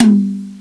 50hitom1.wav